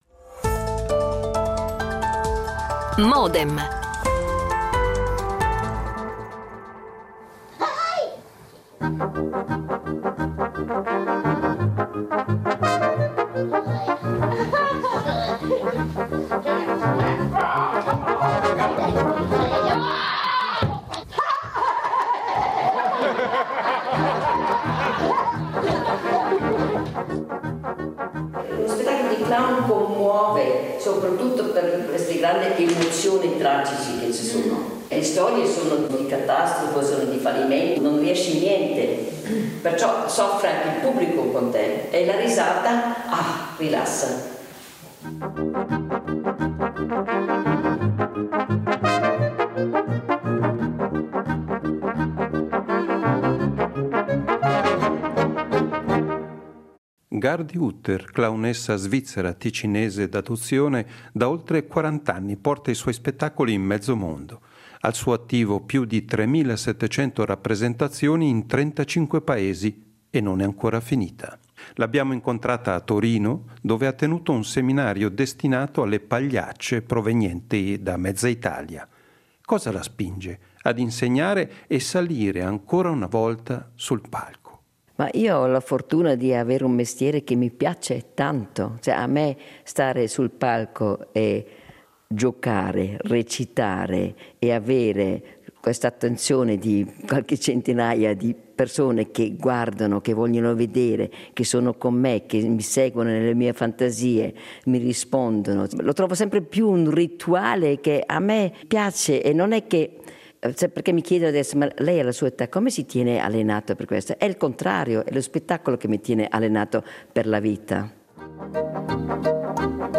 Modem Incontro con la clownessa Gardi Hutter
L'abbiamo incontrata a Torino, dove ha tenuto un seminario destinato alle pagliacce provenienti da mezza Italia.